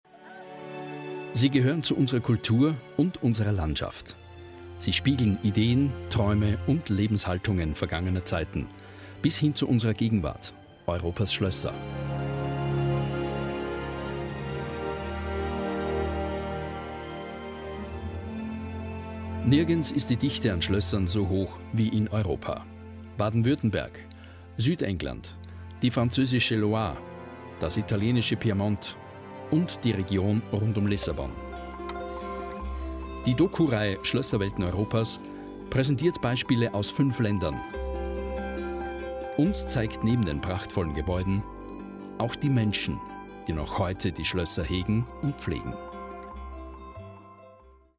Sprecher, Moderator sowie ISO zertifizierter Kommunikations- u. Performancetrainer.
Sprechprobe: Industrie (Muttersprache):